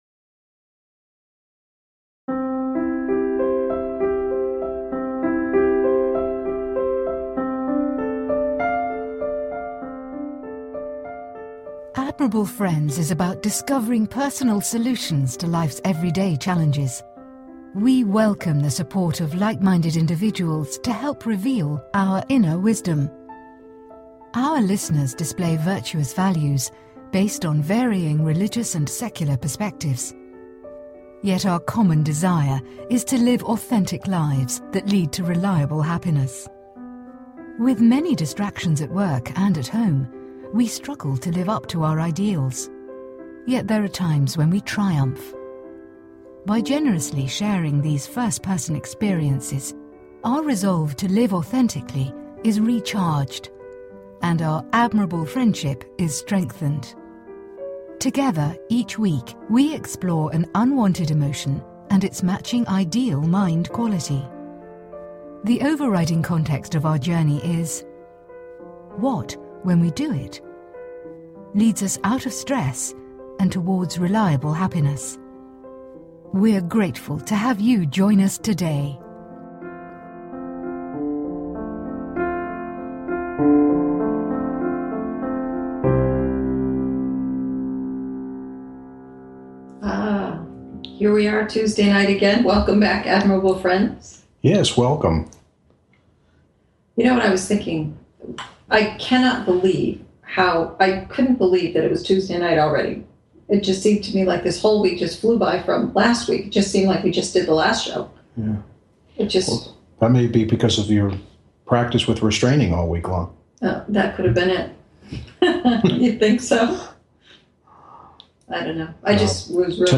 Today's topic is Impatience & Endurance Show Tags Impatience and Endurance Archive Category Health & Lifestyle Kids & Family Philosophy Psychology Mental Health Self Help Admirable Friends Please consider subscribing to this talk show.